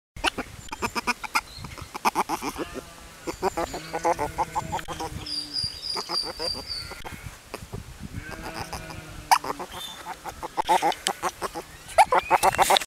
River Otter
Voice
The river otter is able to communicate with others by whistling, growling, chuckling and screaming.
river-otter-call.mp3